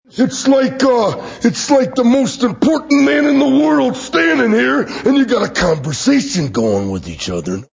Randy Savage Conversation